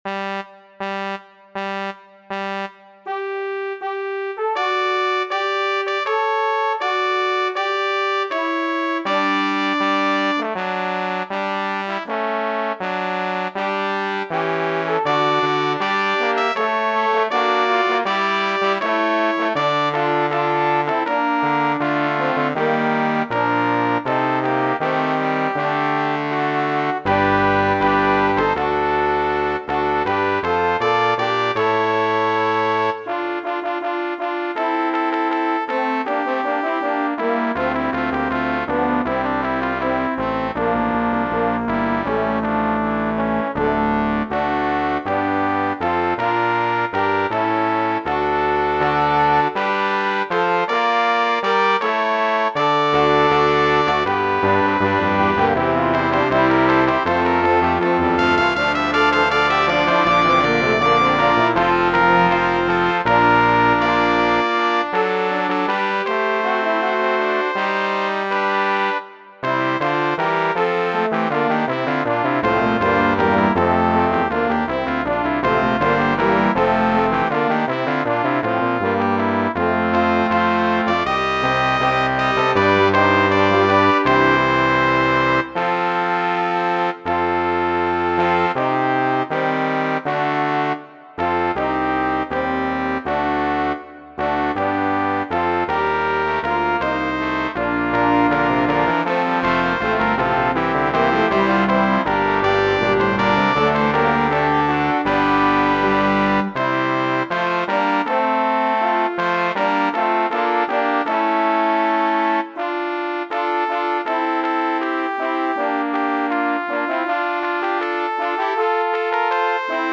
Acht Stimmen in zwei Chören      nach oben